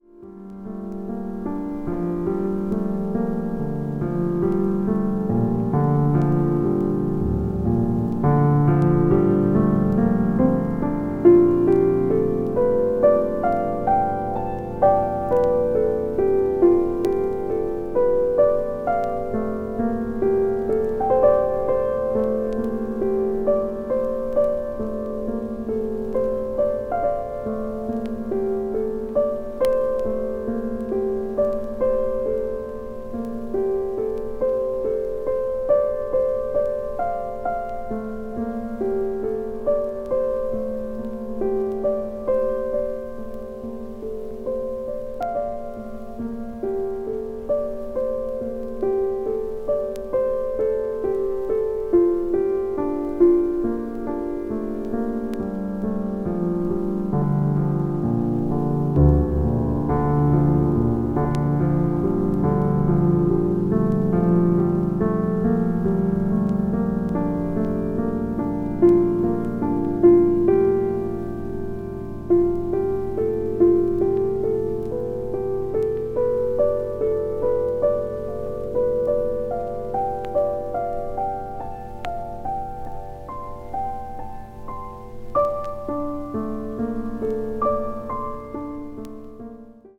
独特の浮遊感を備えた陰りのある淡々とした音風景が極上。